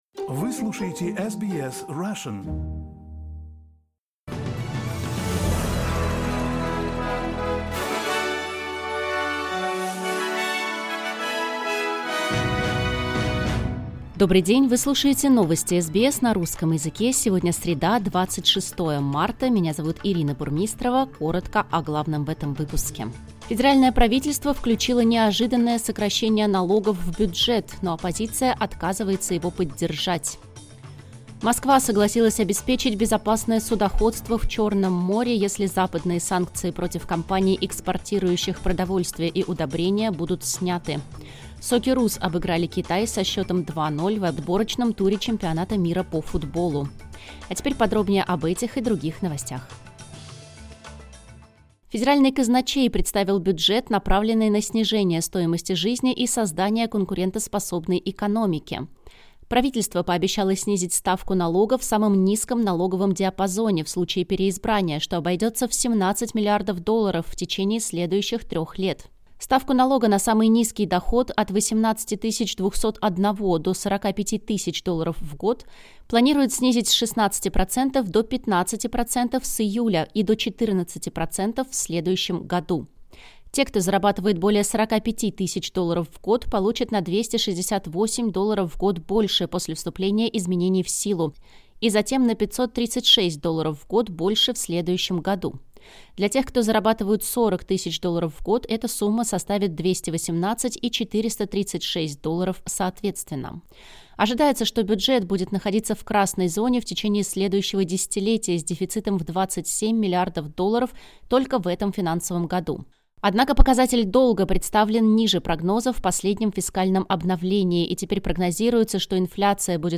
Новости SBS на русском языке — 26.03.2025